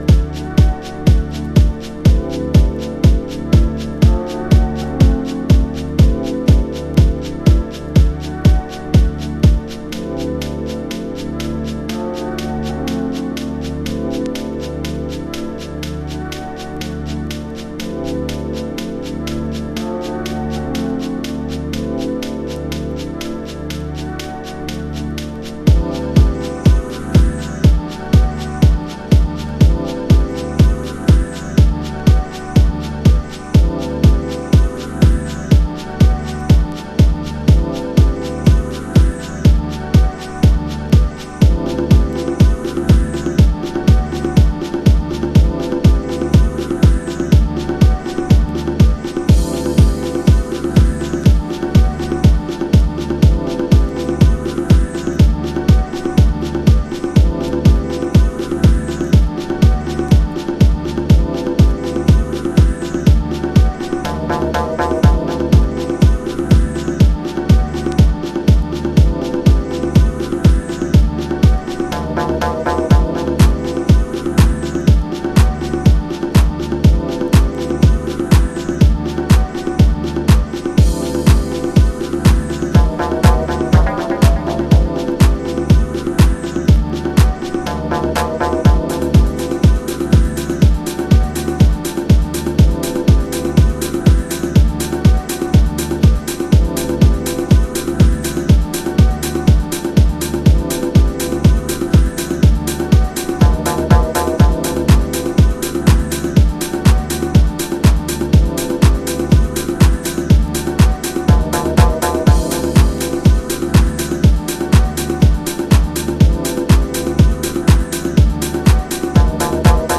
ビートダウンしたビートにミニマルなシンセで物語ります。